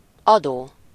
Ääntäminen
IPA : /ˈsteɪʃən/